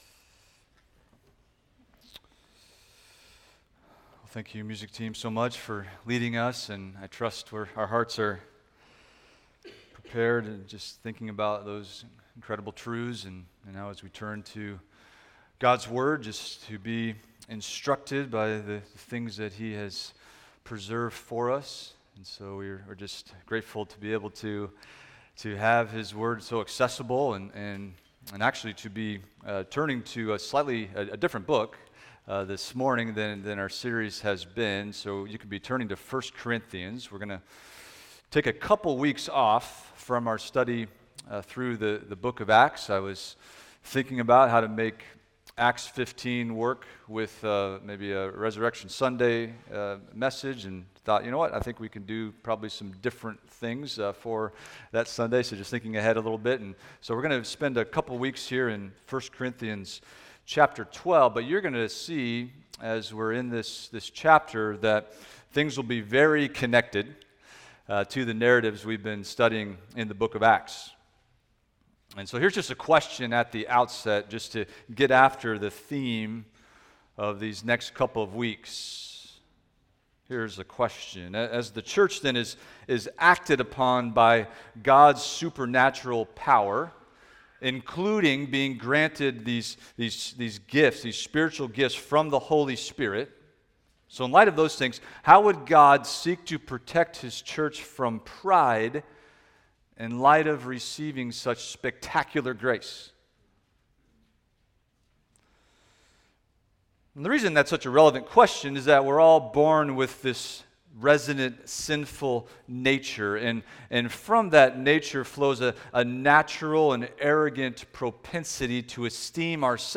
Expository Preaching from 1st Corinthians – A Christ-Exalting Understanding of the Spirit’s Activity in the Church – Part 1